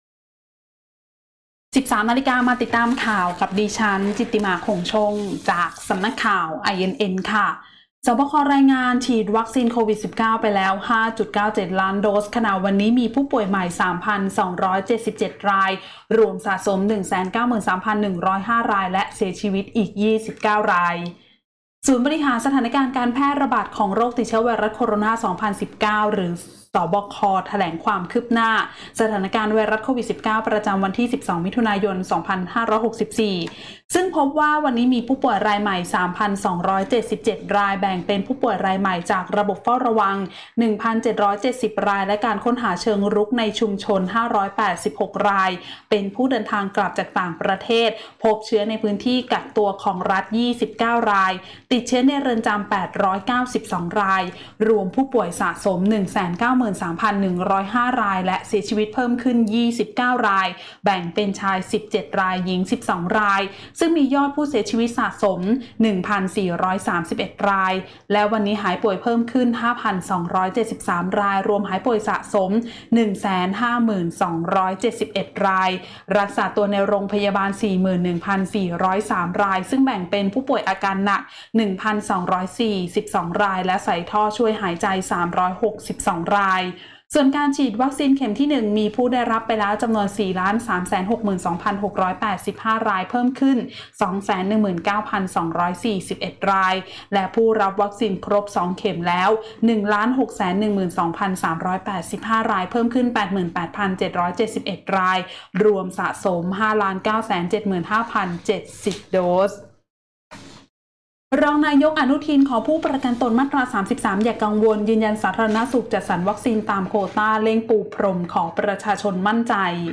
คลิปข่าวต้นชั่วโมง
ข่าวต้นชั่วโมง 13.00 น.